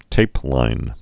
(tāplīn)